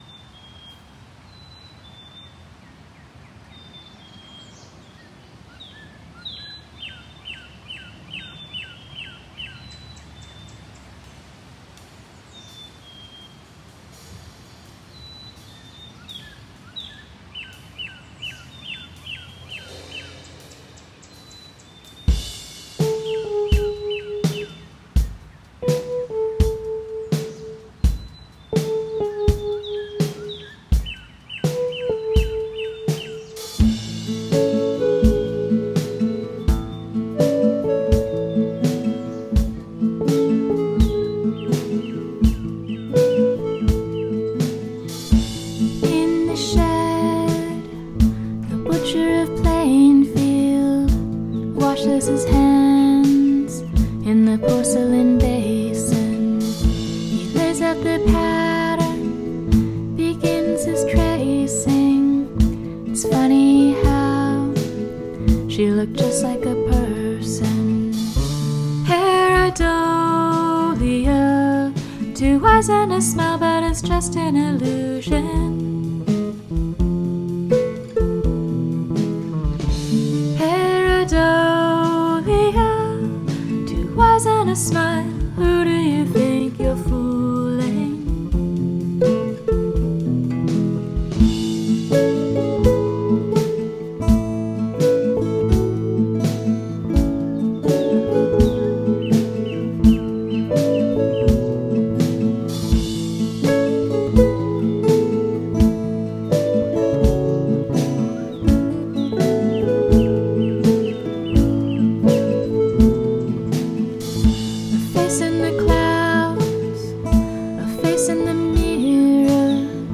Use of field recording